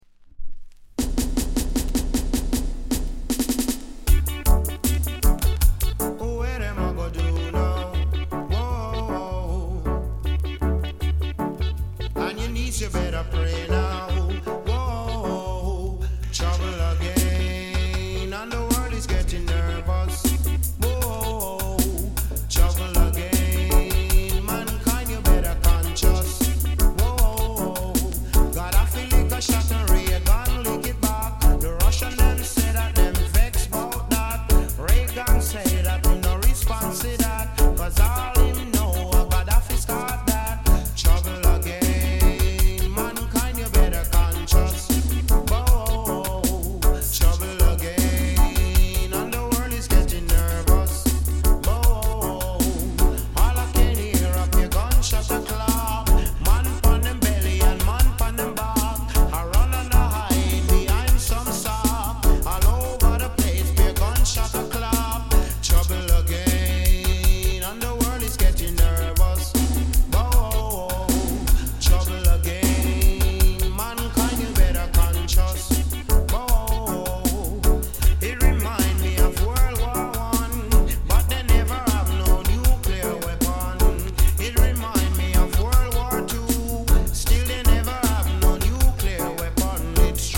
概ね美盤ですが、B面 少しパチつく箇所あり。